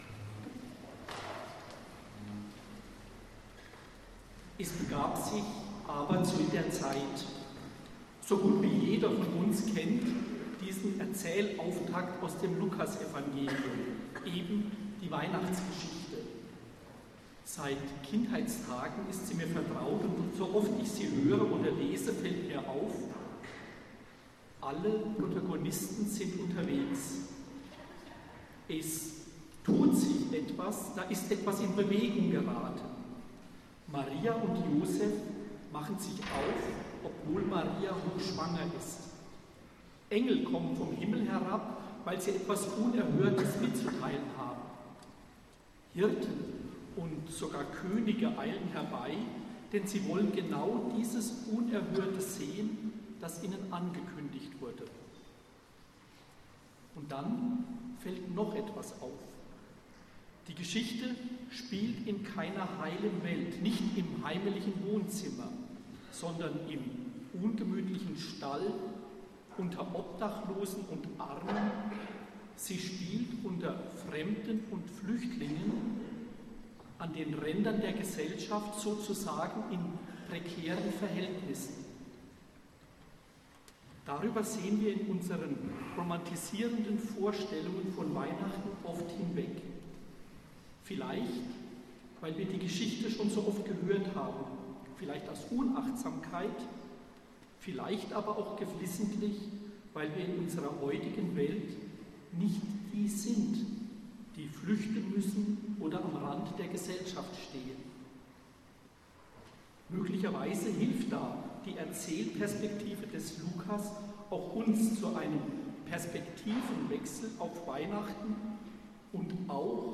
Hier mein Konzertmitschnitt vom 20. Dezember 2022, wie gewohnt im puristischen Schnelldesign für alle, die mitgespielt, mitgesungen oder mitgeübt haben.